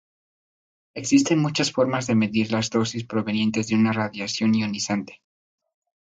Pronúnciase como (IPA) /ˈdosis/